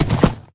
horse_gallop1.ogg